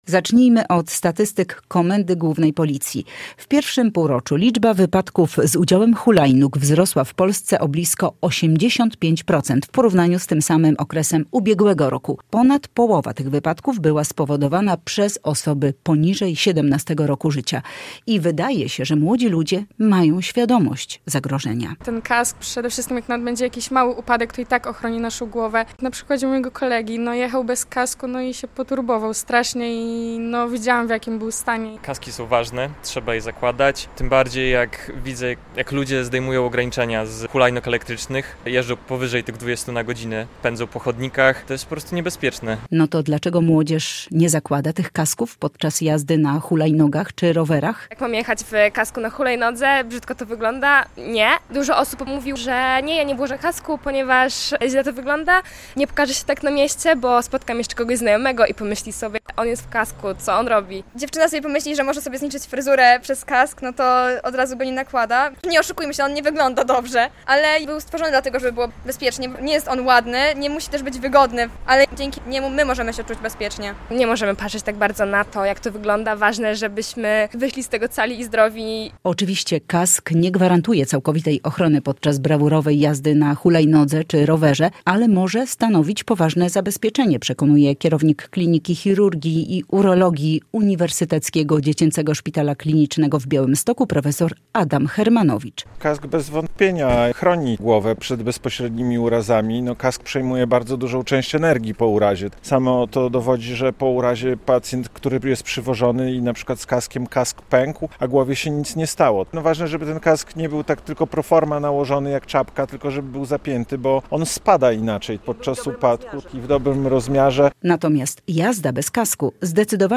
Młodzi białostoczanie, z którymi rozmawialiśmy, przyznają, że nastolatki nie lubią nosić kasków, chociaż są świadomi zagrożenia.